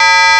max_sound_note_4.wav